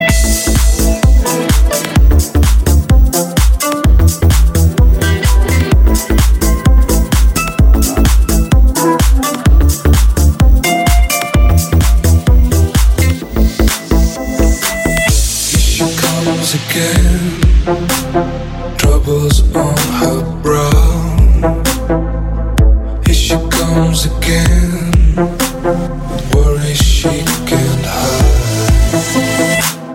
• Качество: 320, Stereo
мужской вокал
Electronic
электронная музыка
club
Electropop
электропоп